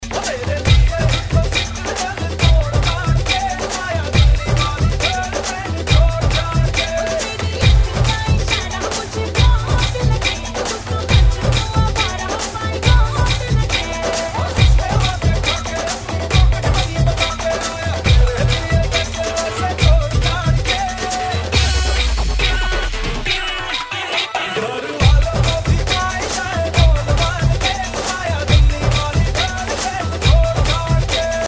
Dandiya Mix Ringtones